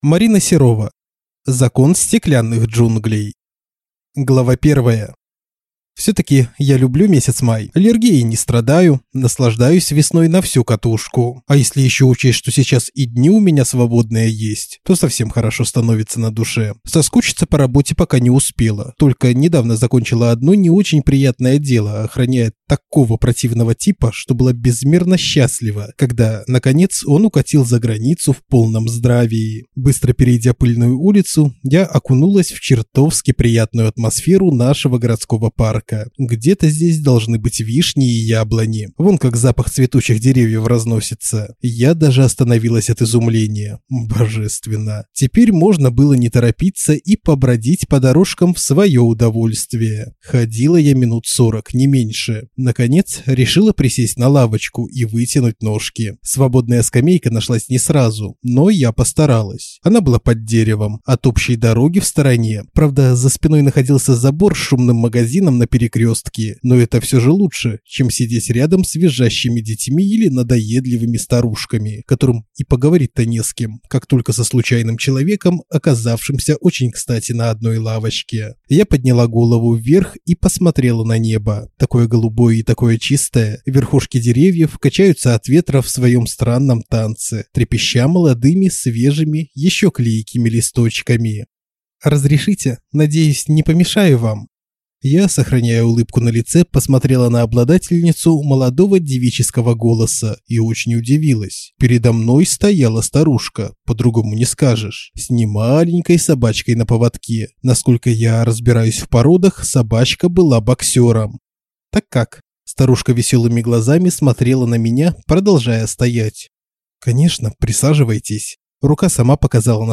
Аудиокнига Закон стеклянных джунглей | Библиотека аудиокниг